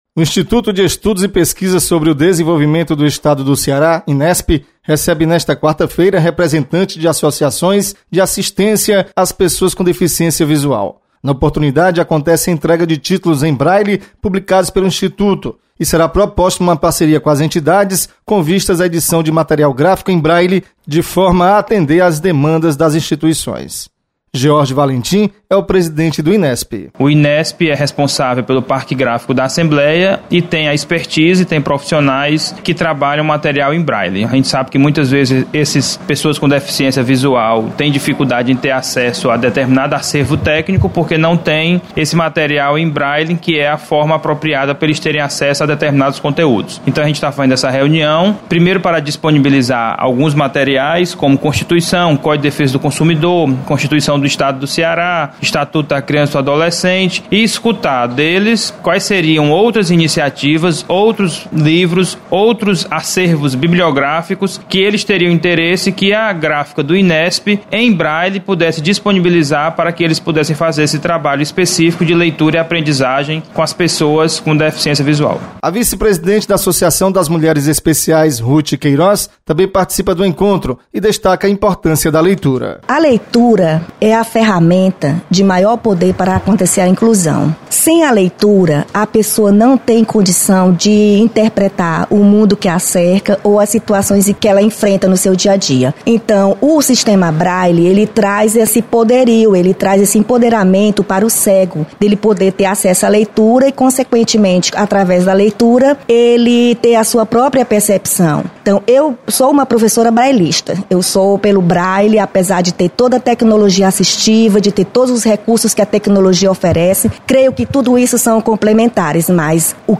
Inesp entrega publicações em braile para associações que cuidam de pessoas com deficiência visual. Repórter